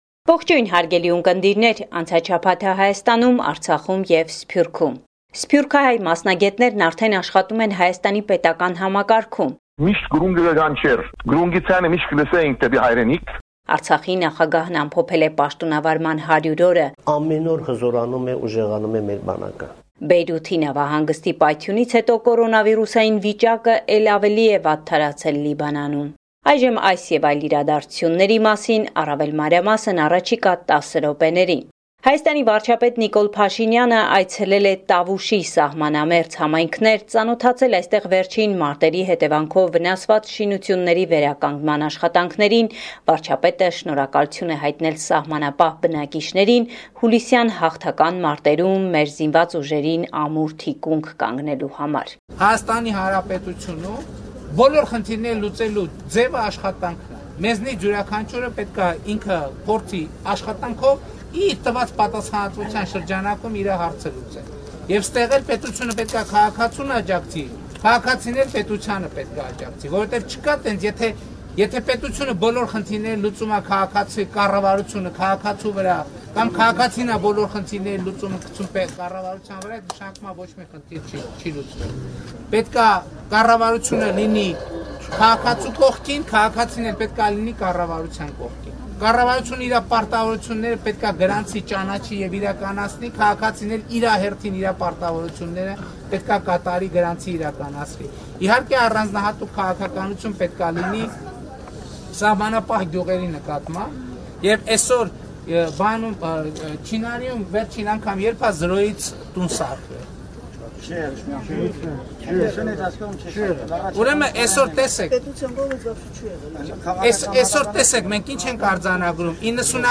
Latest News from Armenia – 8 September 2020